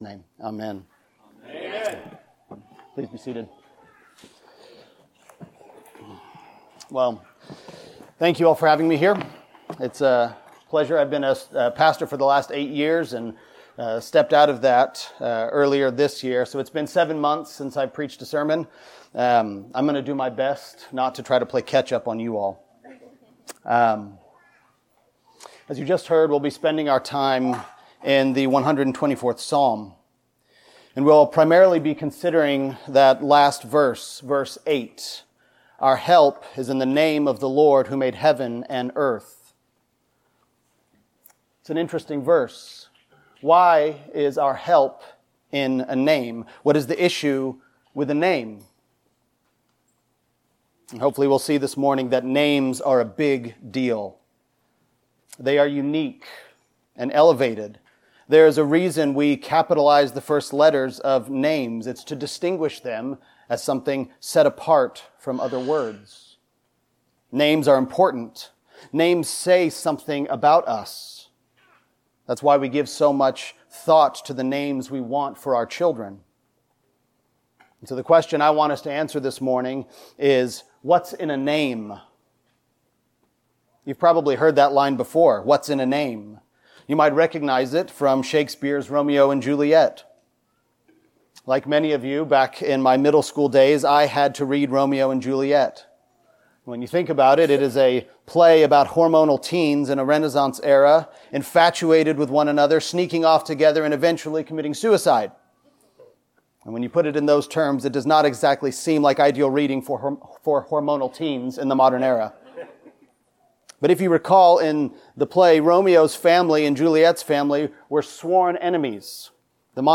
Passage: Psalm 124 Service Type: Sunday Sermon Download Files Bulletin « In the Presence of God